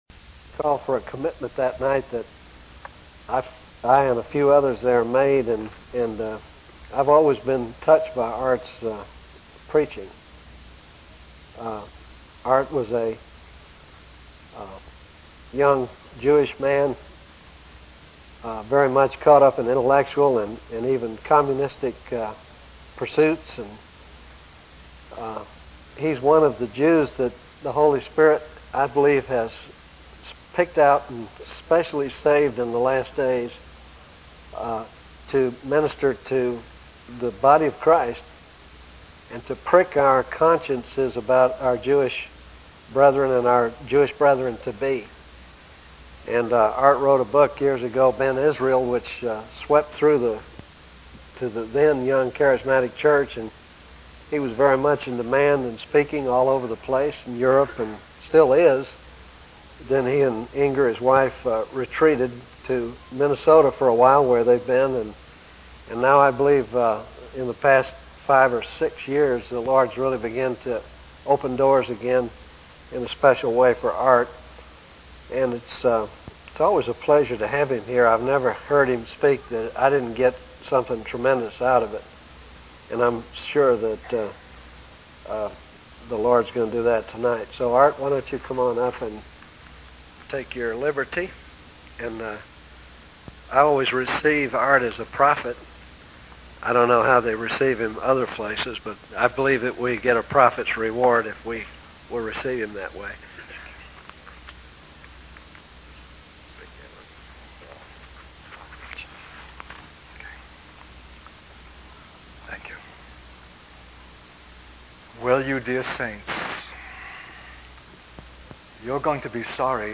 In this sermon, the speaker begins by urging the audience to humble themselves before God and seek His guidance.